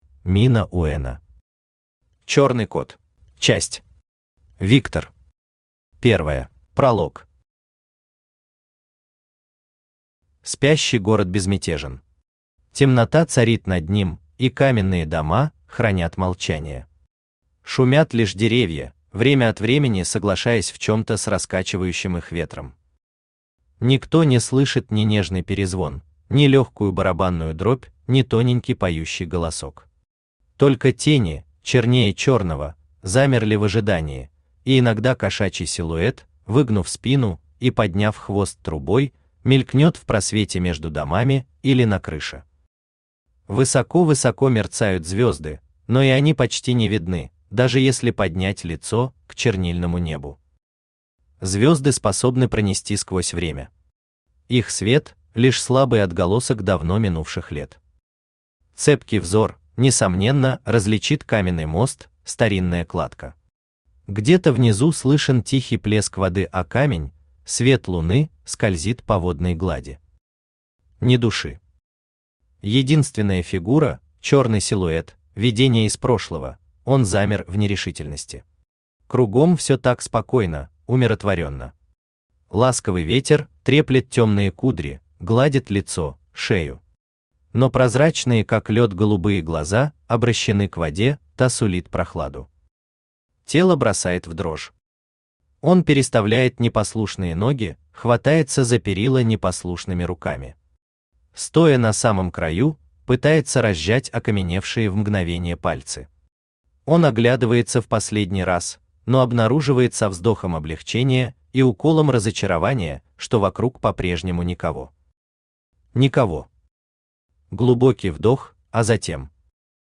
Аудиокнига Чёрный кот | Библиотека аудиокниг
Aудиокнига Чёрный кот Автор Мина Уэно Читает аудиокнигу Авточтец ЛитРес.